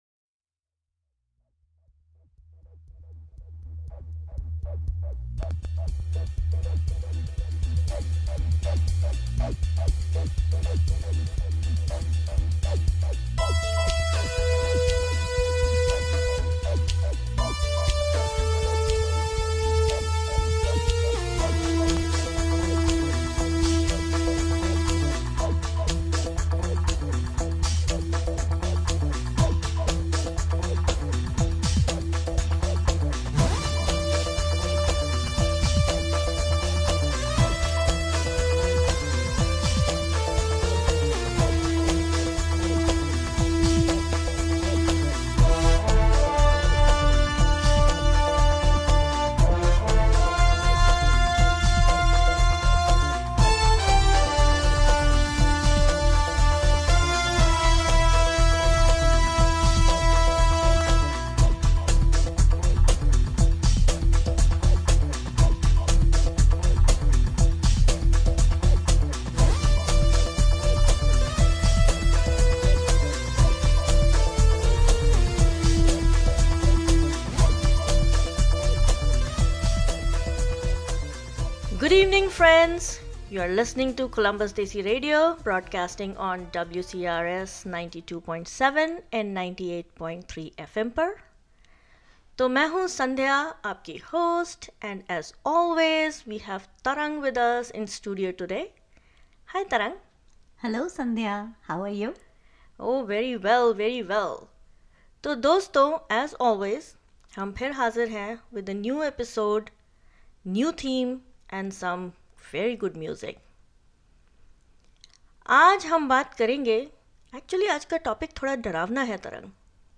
when a voice, no other theatrics, is all that builds suspense...